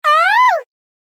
女性の声で「あーぅ」発声するセリフです。
「あーぅ」女性の声 着信音